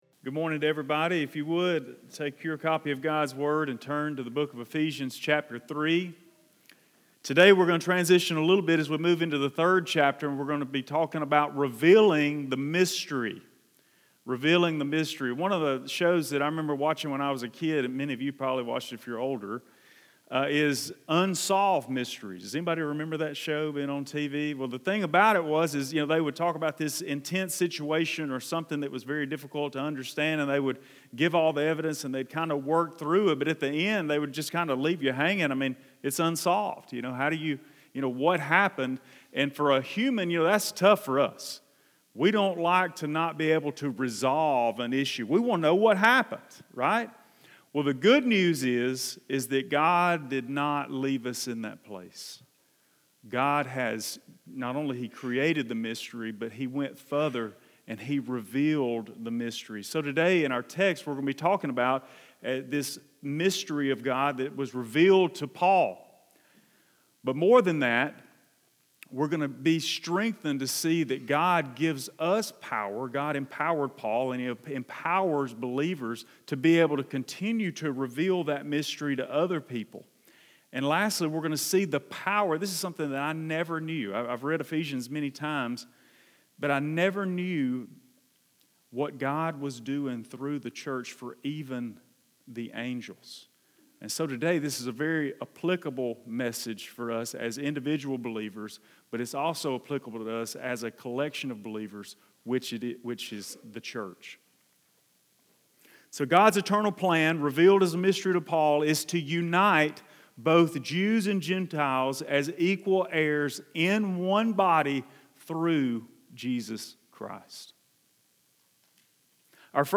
Thankfully, God does not leave us hanging when it comes to His plan of salvation. Today's sermon explains God's mystery that was kept secret for thousands of years and finally revealed through Jesus Christ. This mystery continues to give purpose for believers in 2024.